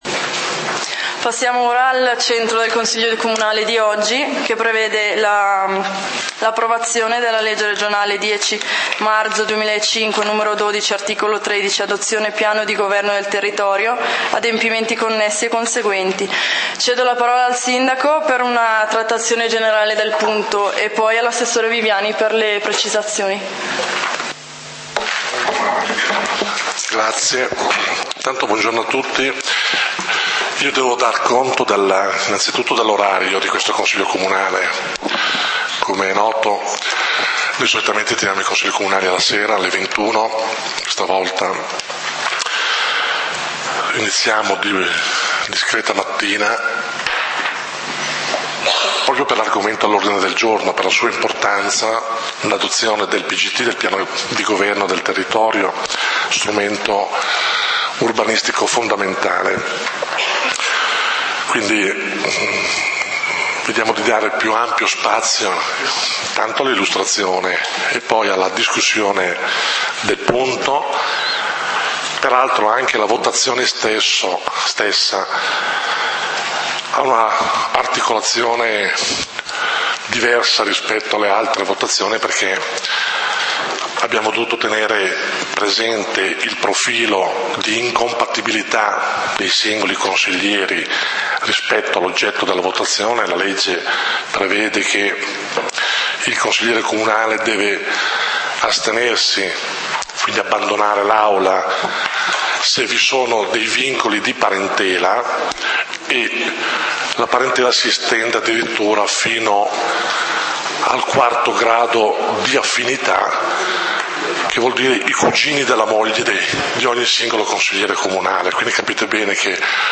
Consiglio comunale di Valdidentro del 30 Dicembre 2013 torna alla lista dei punti Punto 2: l.r. n. 12 del 11.03.2005 – art. 13. adozione del piano di governo del territorio (p.g.t.) – adempimenti connessi e conseguenti; Sindaco Ezio Trabucchi: introduzione Scarica i file di questo punto: file video in formato flv file audio in formato mp3